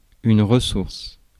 Ääntäminen
IPA: /ʁə.suʁs/